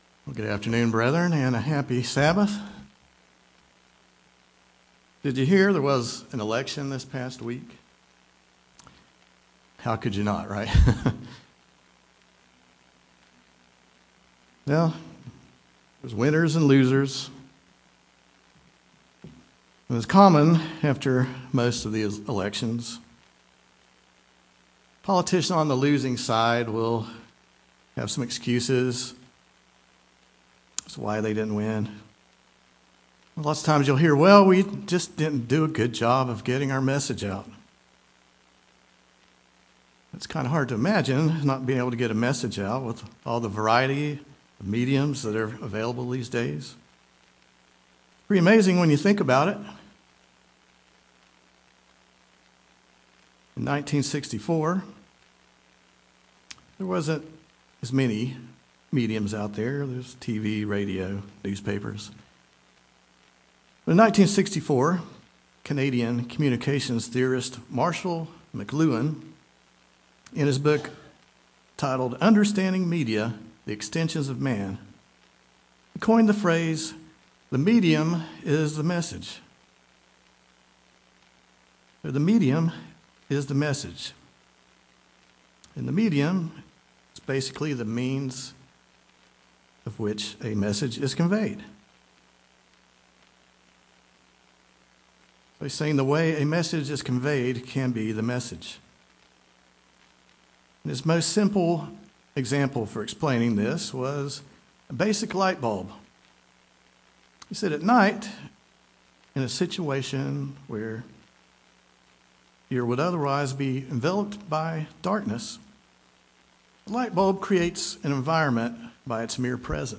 Given in Greensboro, NC